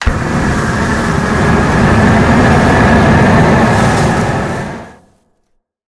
flamethrower_shot_03.wav